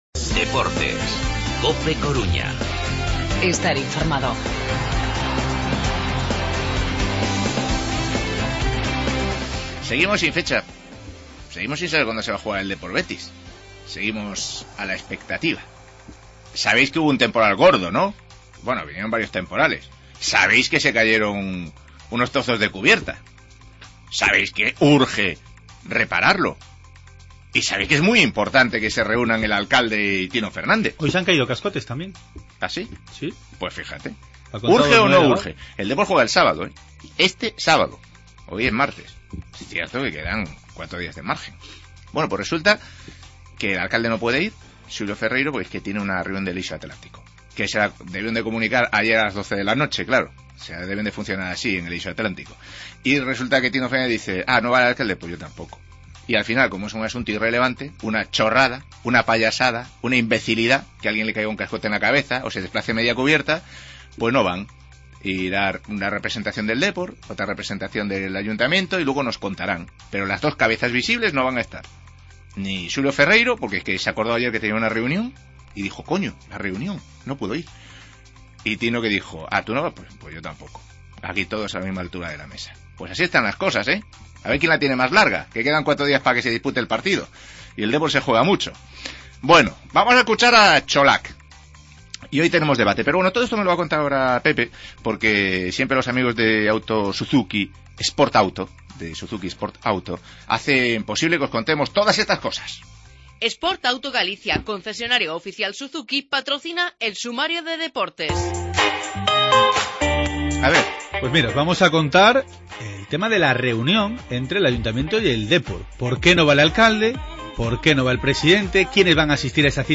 Deportivo y Ayuntamiento se reunirán mañana para hablar de las cubiertas de Riazor, pero finalmente sin la presencia de Xulio Ferreiro y Tino Fernández. Escuchamos las palabras de Çolak y debatimos: ¿Por qué no es capaz de cerrar los partidos el Deportivo?